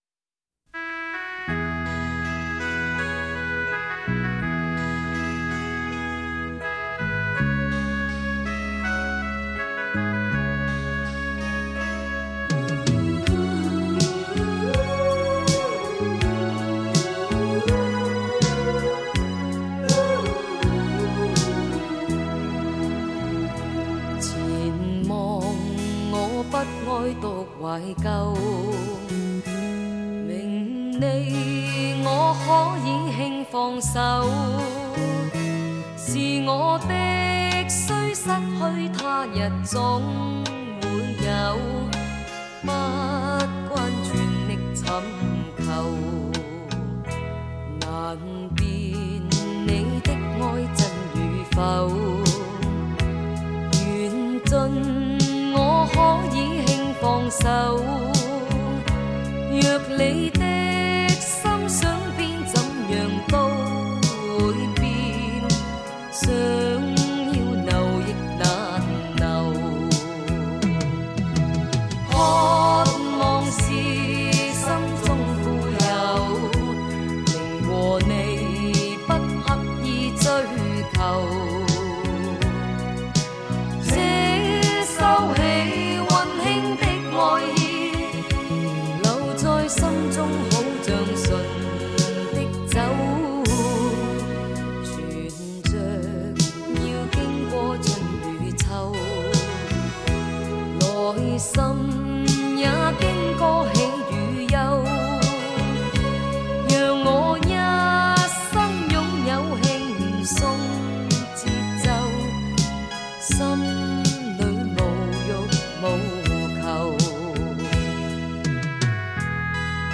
粤语老歌经典